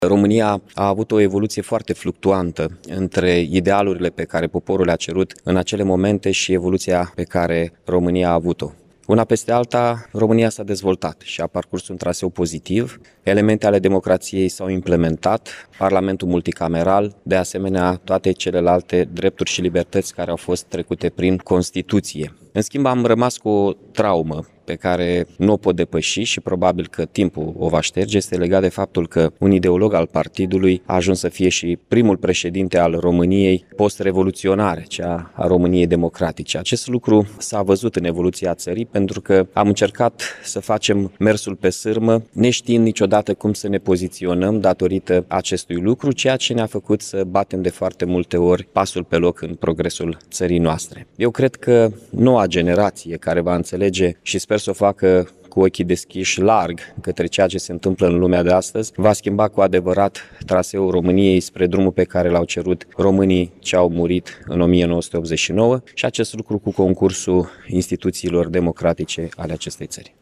În deschiderea manifestărilor, edilul Mihai Chirica a subliniat faptul că, în mod cert, Revoluția a început la Iași, dar ulterior, aceasta a avut o evoluție sinuoasă și, în cele din urmă, eșalonul secund al Partidului Comunist Român a confiscat evenimentele.